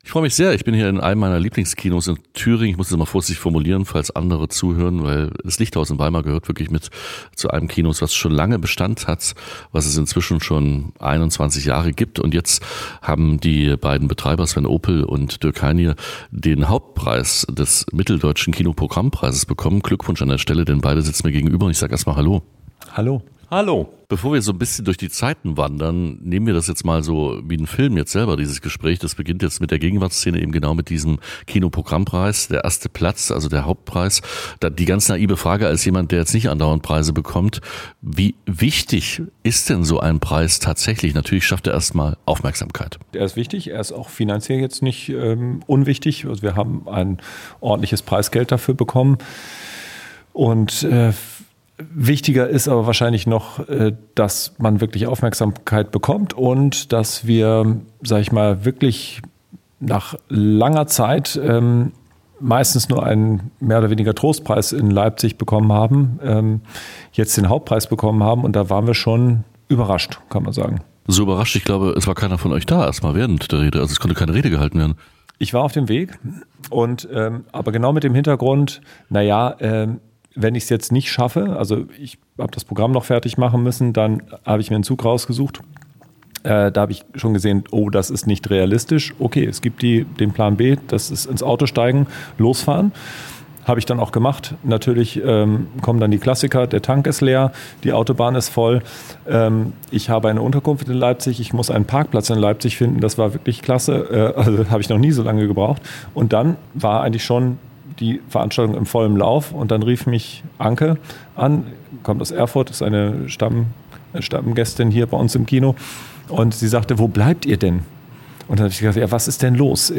INTERVIEW LICHTHAUS.mp3